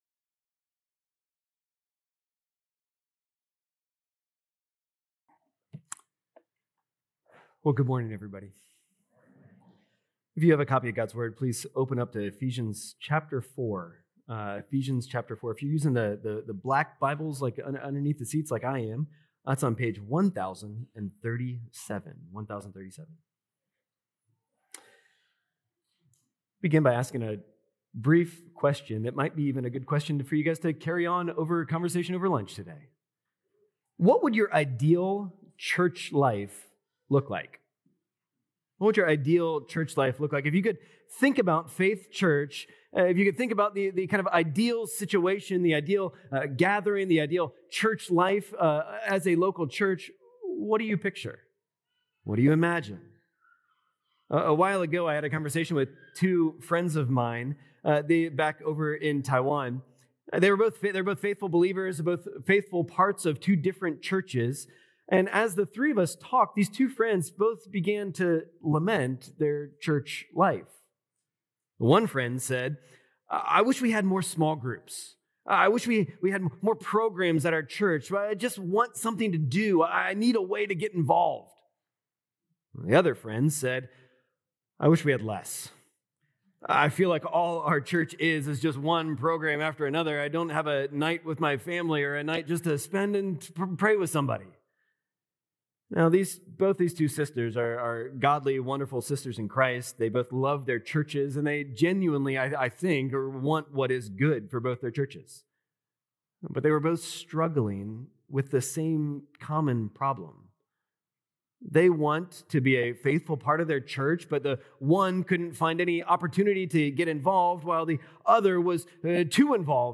Faith Church Sermon podcast